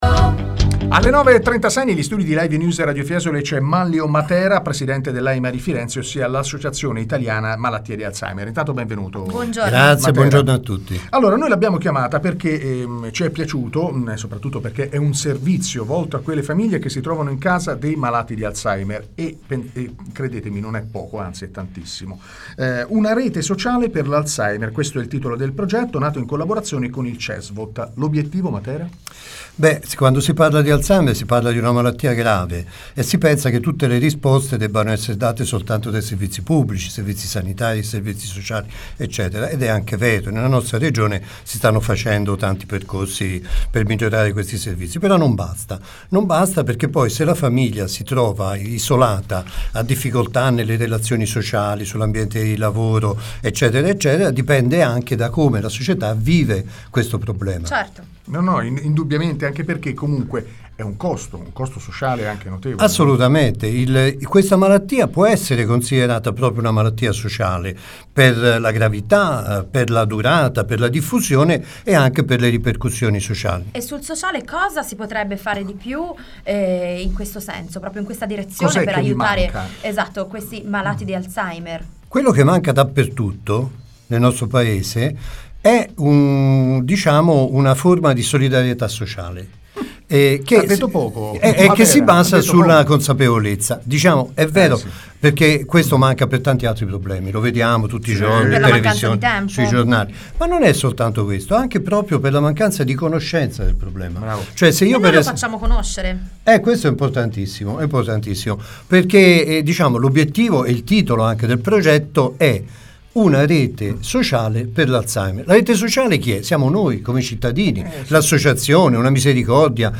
La malattia di Alzheimer e le reti sociali, il presidente ospite a Radio Fiesole